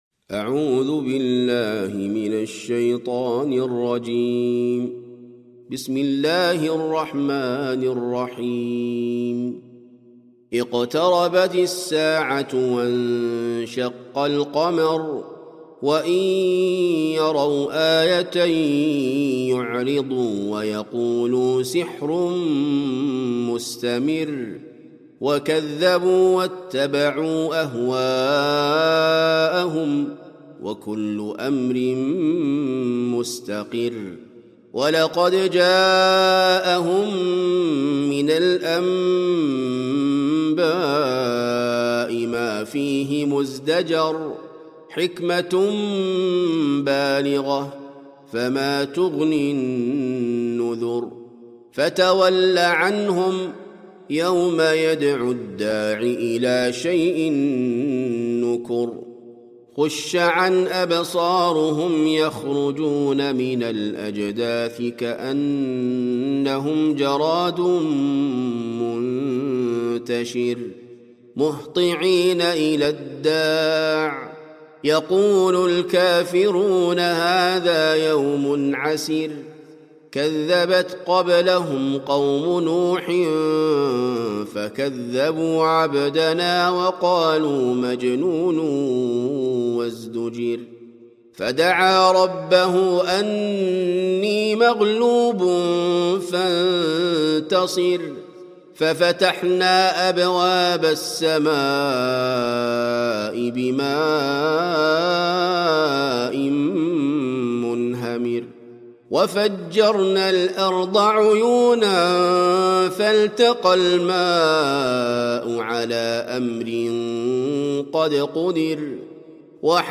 سورة القمر - المصحف المرتل